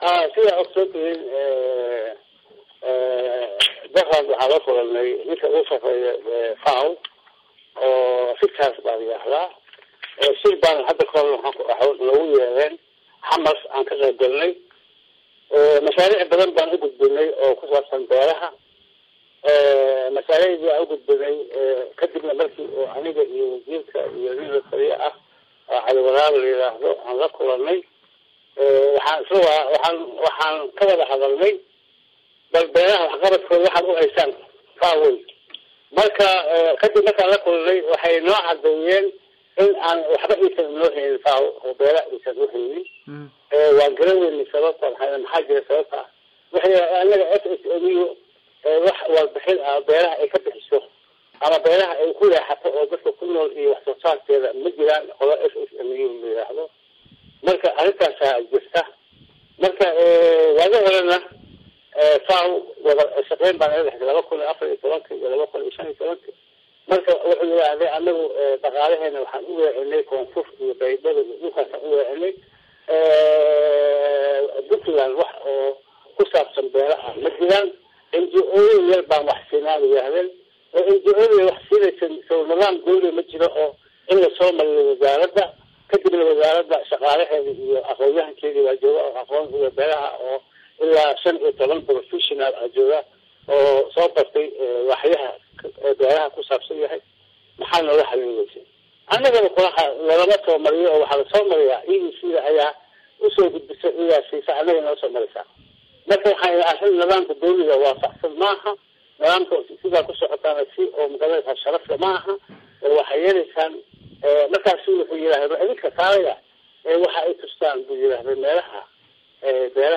Dhagayso Agaasimaha Guud ee wasaaradda beeraha iyo waraabka Puntland Ibrahim Xareed oo Radio Garowe uu waraystay